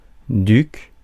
Prononciation
Prononciation France: IPA: [dyk] Paris Le mot recherché trouvé avec ces langues de source: français Traduction Substantifs 1.